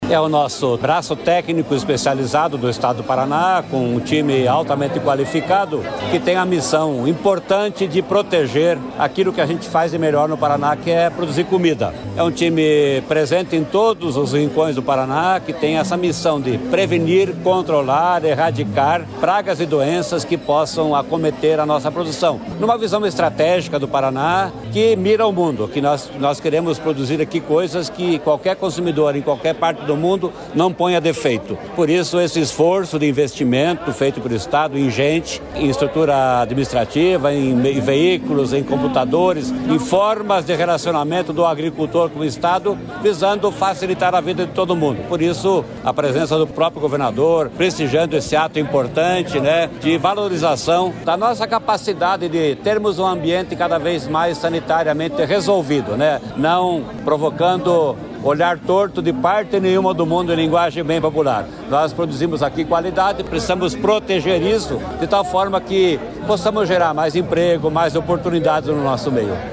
Sonora do secretário da Agricultura e do Abastecimento, Norberto Ortigara, sobre a entrega de veículos e computadores para a Adapar